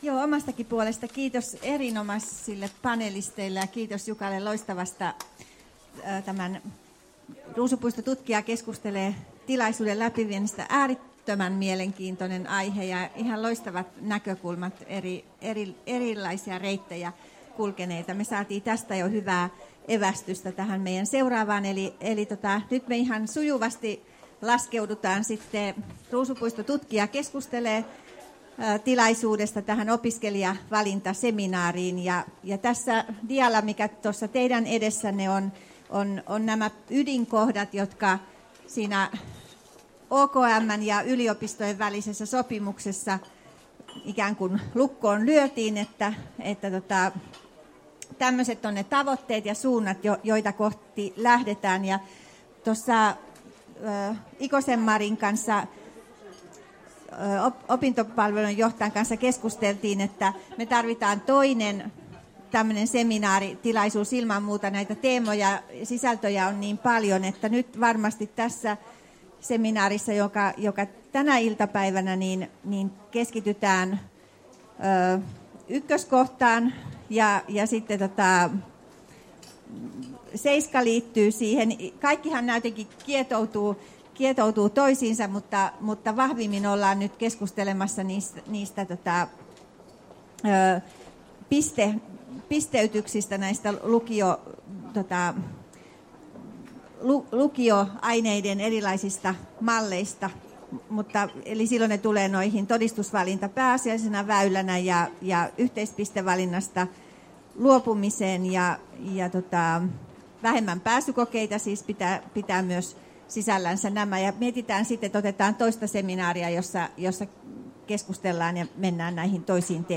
JY:n opiskelijavalintaseminaari